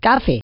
voice_coffee.wav